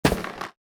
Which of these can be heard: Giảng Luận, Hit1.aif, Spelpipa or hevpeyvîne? Hit1.aif